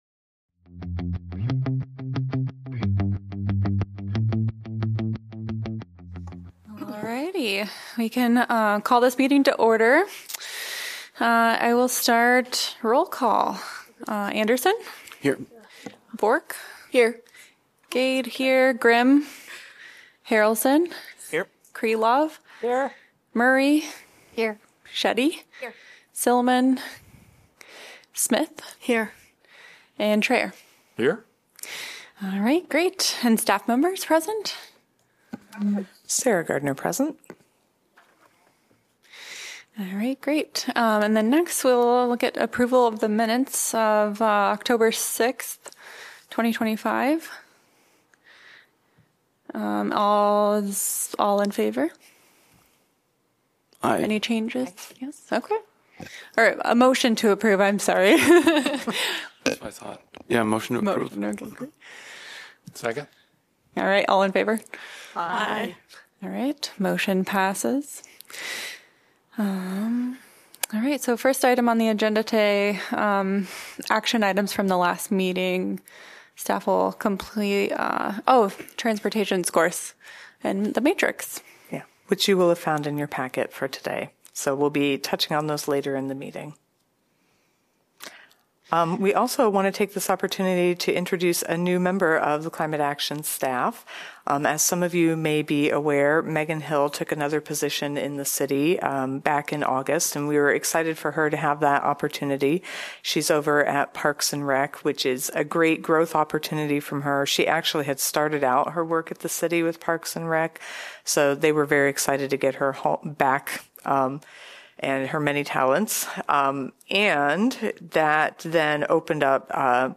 Regular monthly meeting of the Climate Action Commission, rescheduled from November 3.